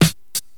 • Sparkle Snare Sample E Key 114.wav
Royality free snare one shot tuned to the E note. Loudest frequency: 2726Hz
sparkle-snare-sample-e-key-114-QRM.wav